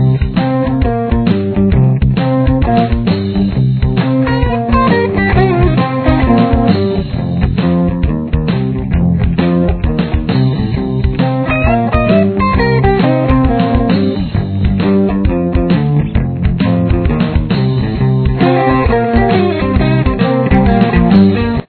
Your basic 12-bar blues progression starts here.
Guitar 2 (lead)